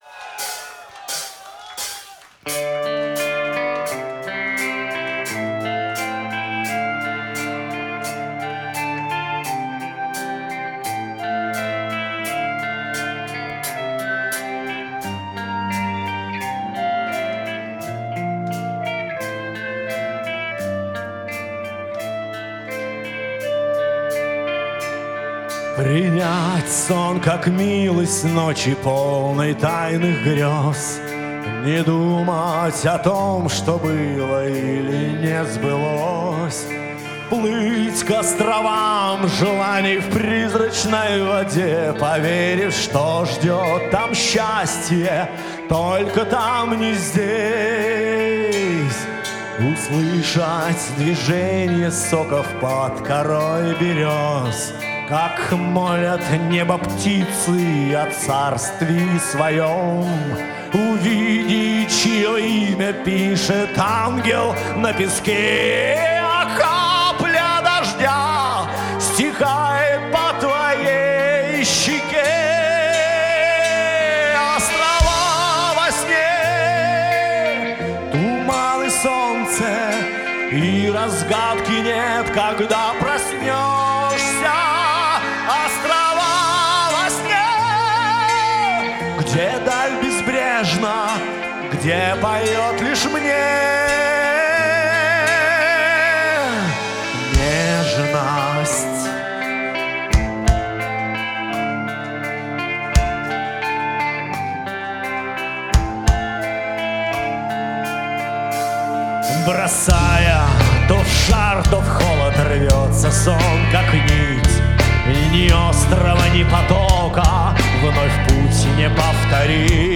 Запись с концерта в Туле, 05.04.2012.
Сильно, красиво, но грустно.